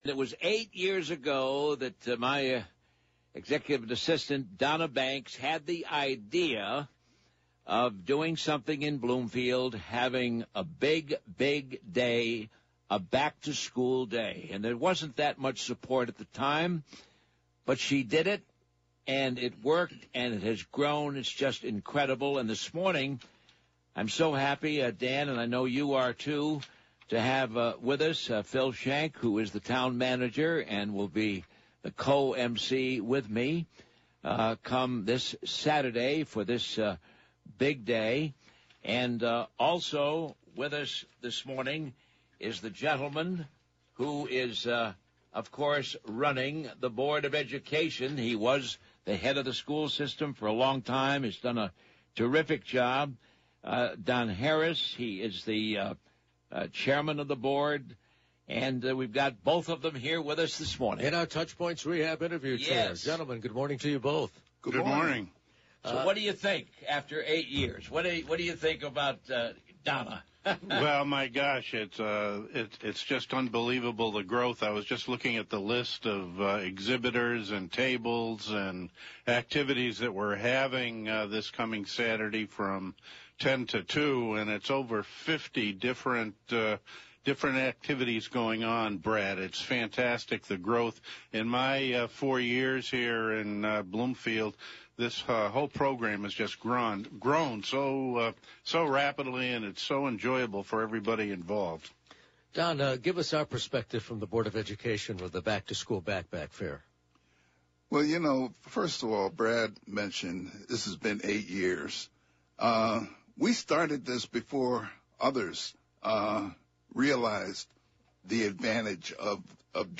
The town of Bloomfield, CT is getting ready for hits Back To School Backpack Fair on Saturday, Aug. 19. Bloomfield Town Manager Phil Schenck and Board of Education Chairman Don Harris have all the details in this interview.